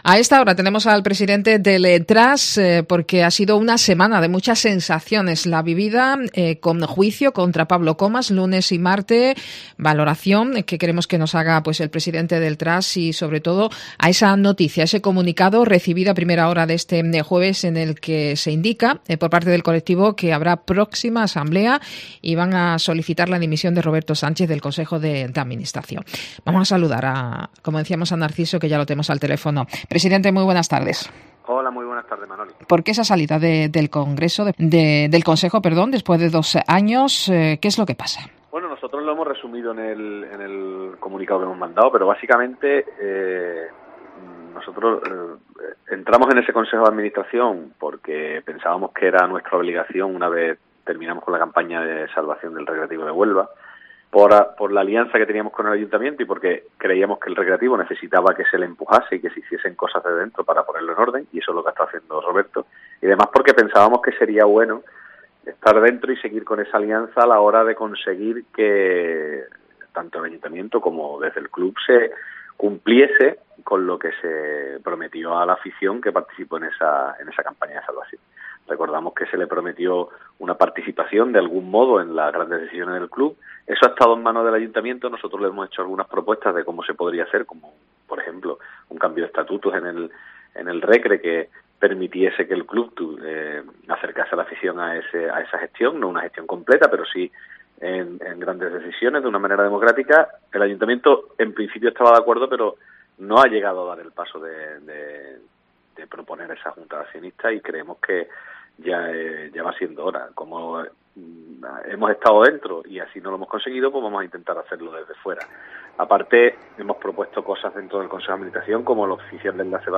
Entrevista al Presidente del Trust